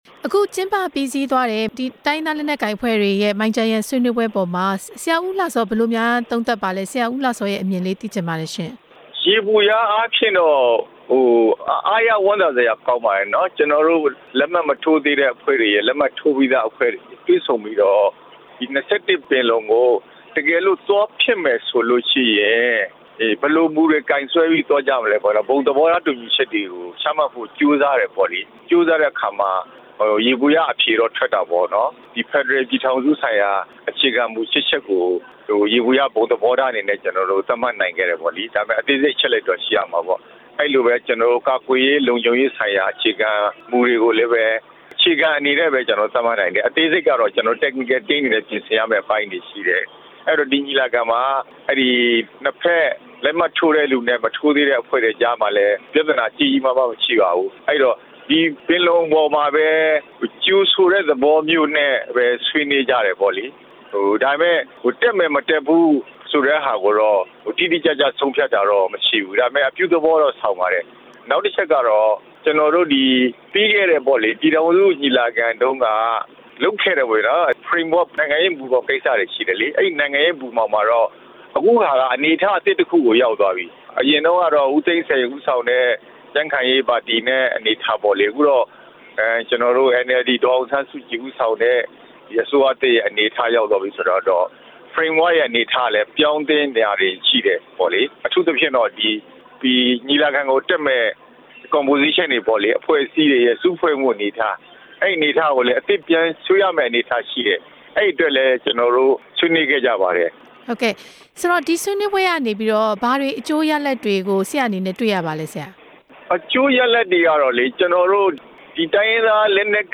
ရခိုင်အမျိုးသားပါတီ ANP လွှတ်တော်ကိုယ်စားလှယ် ဦးဦးလှစောနဲ့ မေးမြန်းချက်